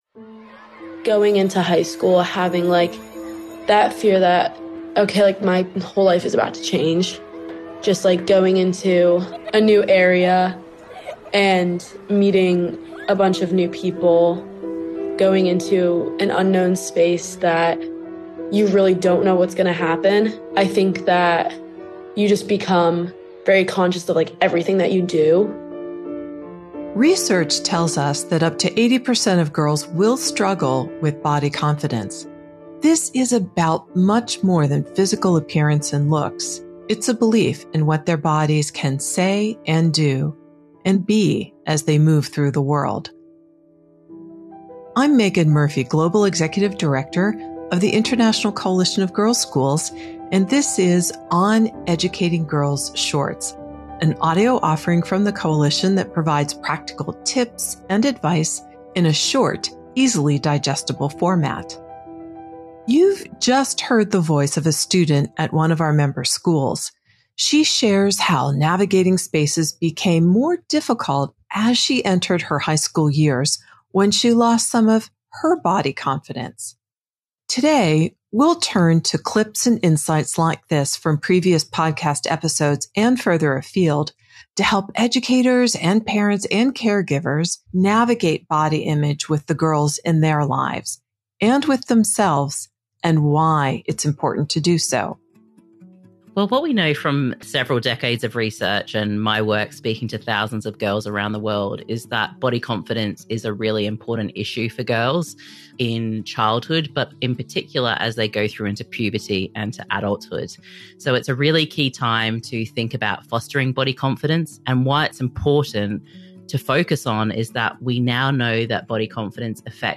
is a robust global conversation among experts who are passionate about the education and healthy development of girls. What is good for girls is at the centre of each episode, as listeners are offered resources, language, tips and programs for educating and empowering young women by those who educate, work with and understand girls.